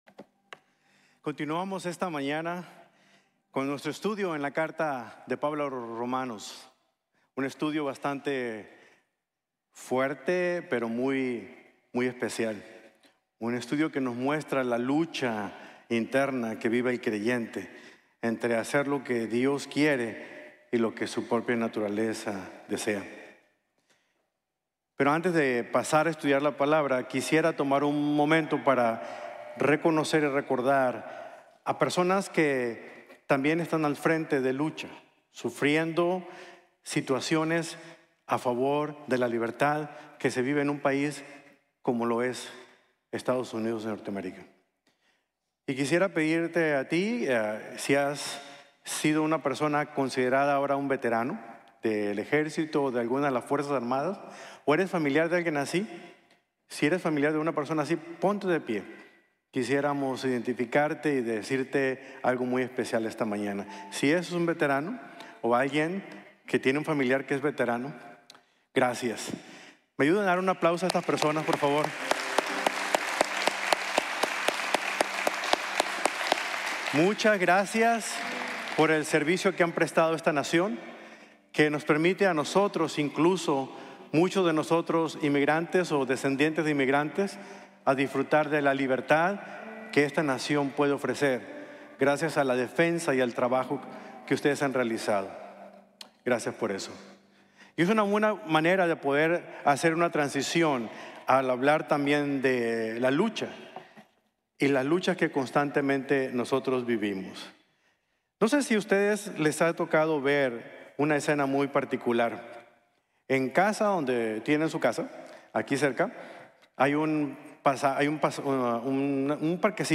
La Lucha es Real | Sermon | Grace Bible Church